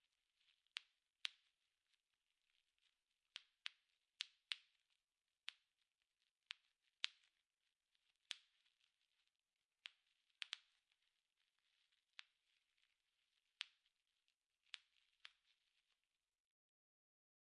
OWI " 爆裂的爆米花
描述：使用气泡包装创建。
Tag: OWI 愚蠢 气泡